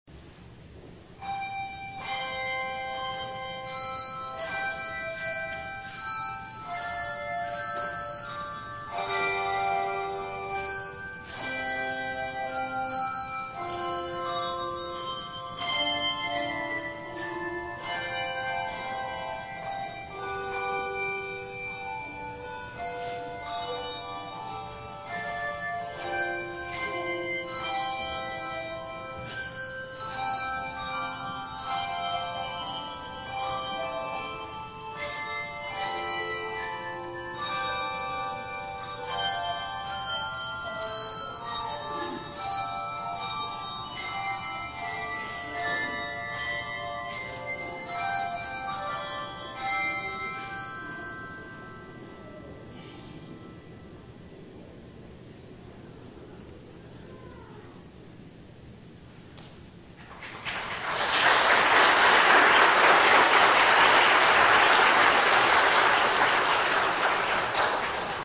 The Second Reformed Junior Bell Choir plays "Away in a Manger" arranged by Peggy Thompson
Handbell Music